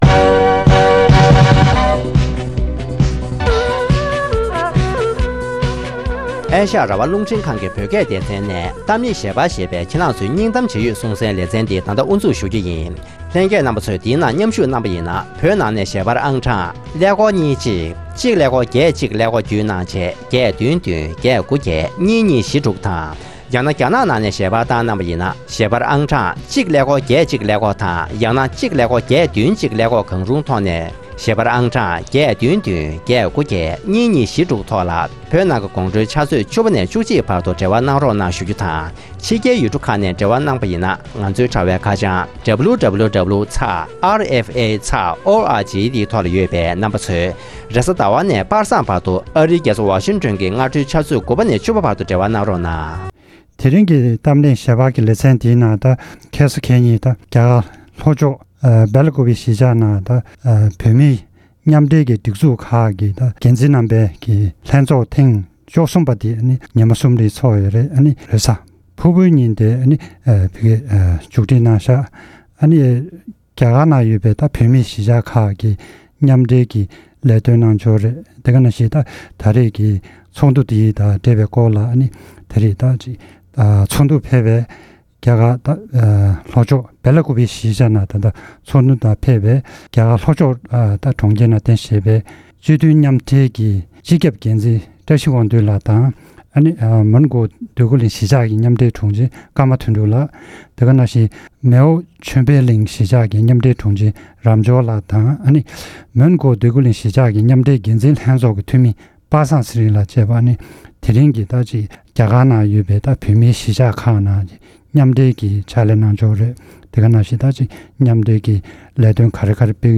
ཐེངས་འདིའི་གཏམ་གླེང་ཞལ་པར་གྱི་ལེ་ཚན་ནང་དུ།